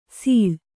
♪ sīḷ